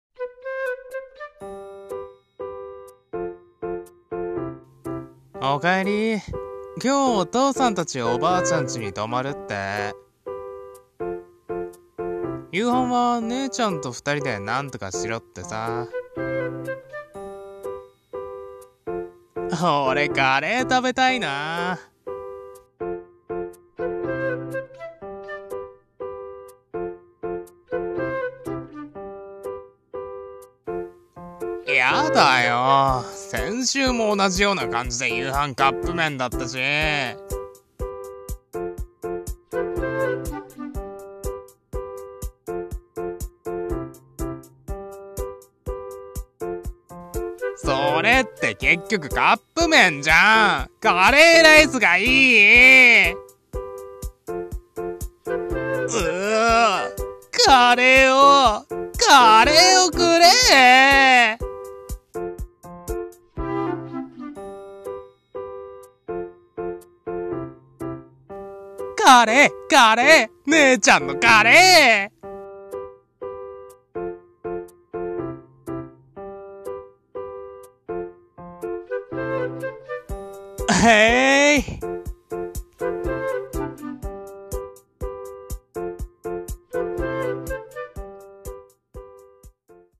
【声劇台本】カレーがいい